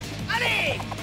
ssbu-the-many-voices-of-sonic-the-hedgehog-audiotrimmer.mp3